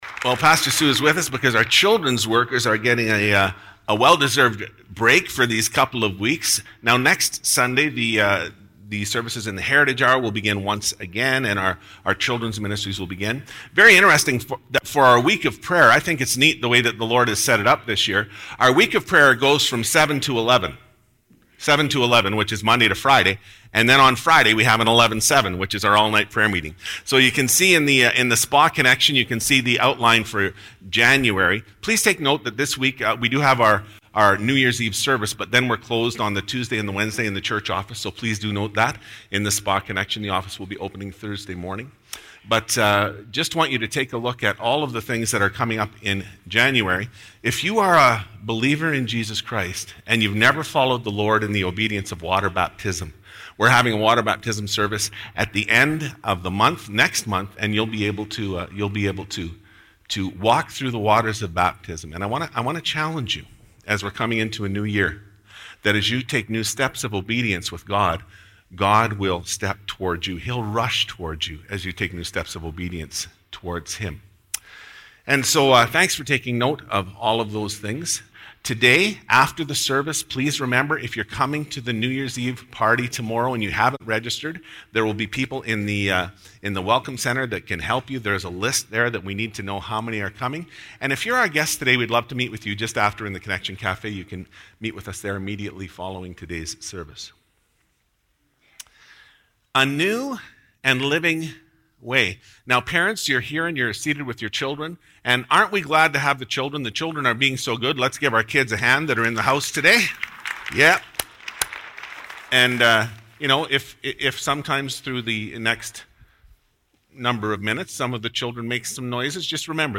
Sermons | Southside Pentecostal Assembly